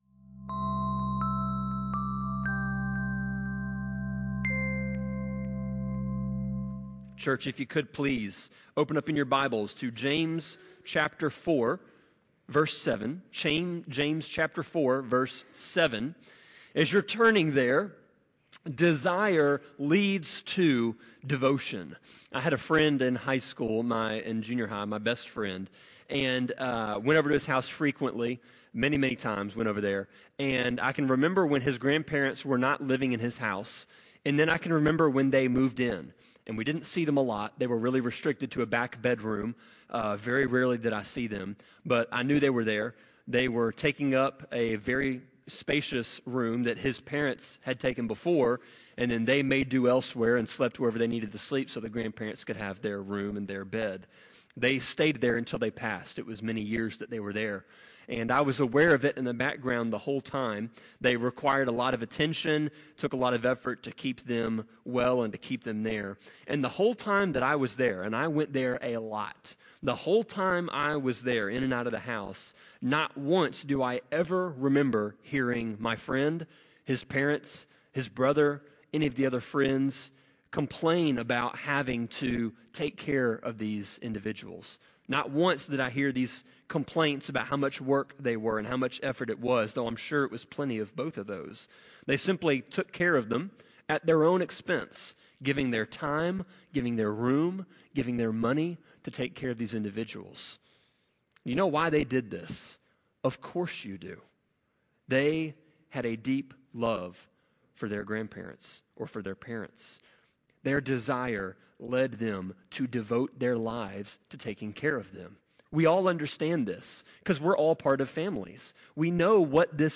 Sermon-25.7.20-CD.mp3